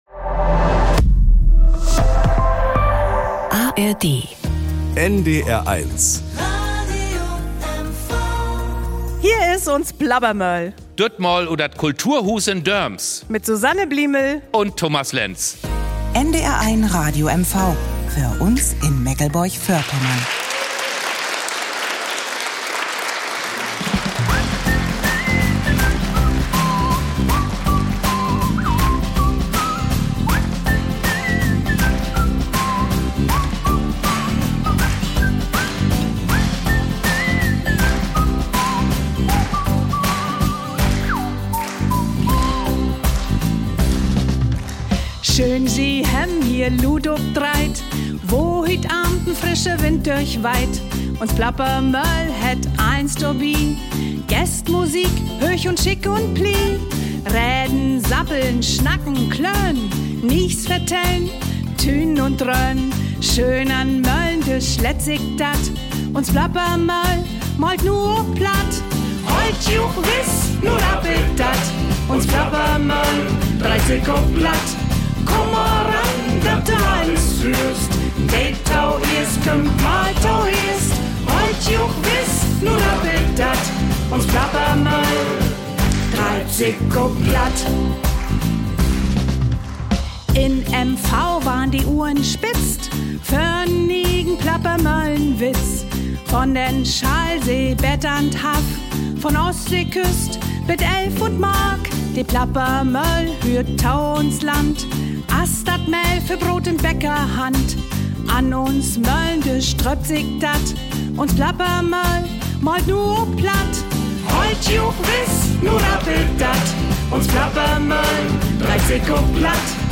Drei Gäste am Moehlendisch - drei Varianten Plattdeutsch - und viele, viele gute Geschichten